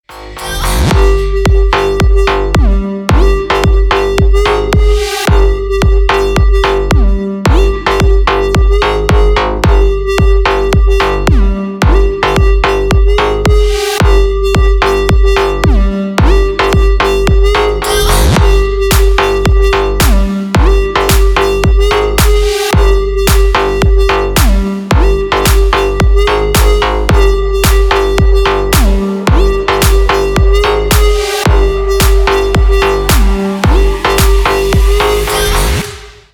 Рингтоны на клубные мотивы
• Качество: Хорошее